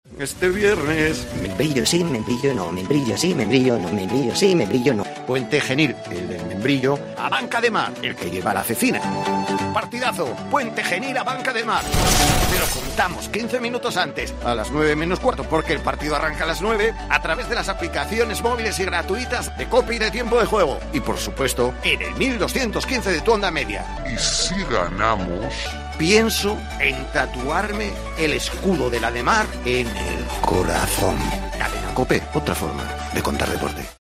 Escucha la cuña promocional del partido Puente Genil - Ademar el día 24-09-21 a las 21:00 h en el 1.215 OM